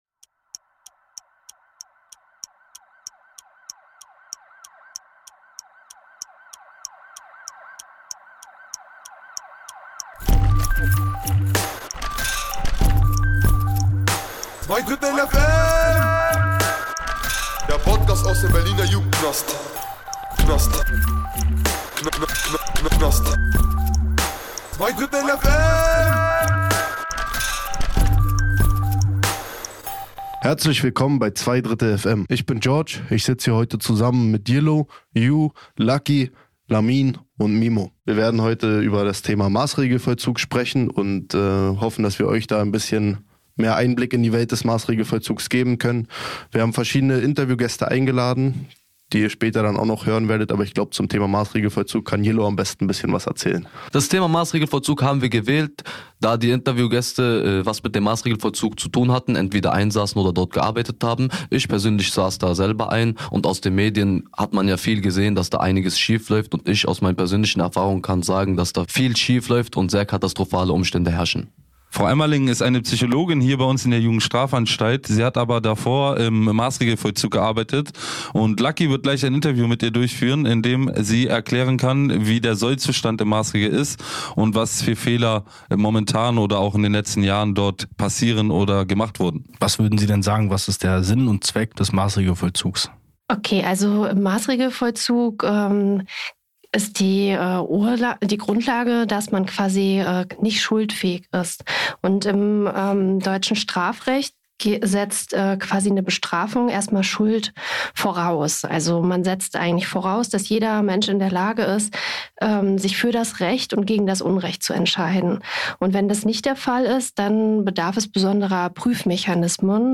Wir haben uns mit zwei ehemaligen Patienten und einer Psychologin unterhalten.